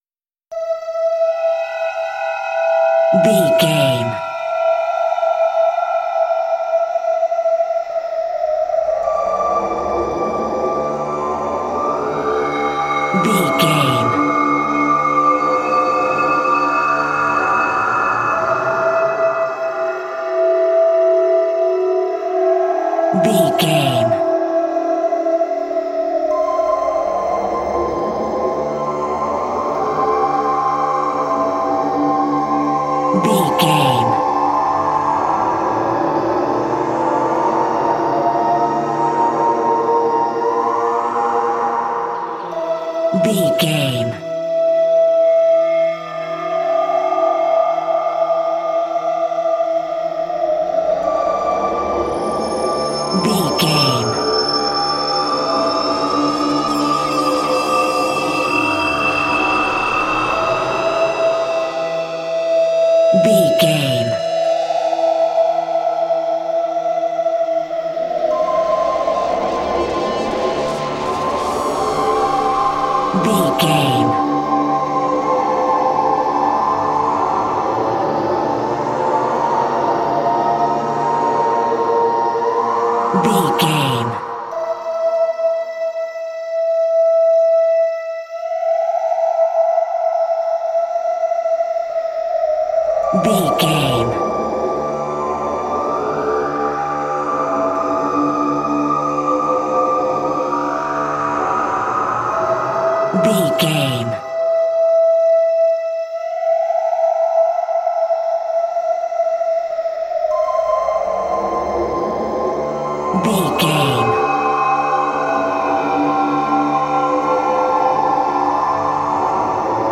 Atonal
Slow
scary
ominous
dark
haunting
eerie
synthesiser
spooky
horror music